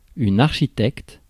Ääntäminen
Ääntäminen France: IPA: [aʁʃitɛkt] Haettu sana löytyi näillä lähdekielillä: ranska Käännös Ääninäyte Substantiivit 1. architect US Suku: m .